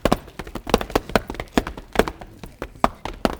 FOOTSTOMP2-L.wav